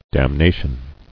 [dam·na·tion]